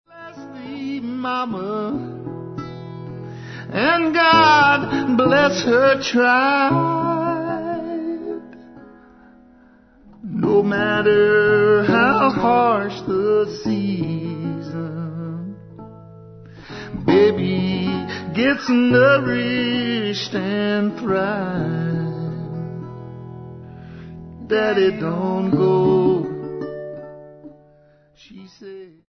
lead vocals, guitar, dulcimer
Recorded at General Store Recording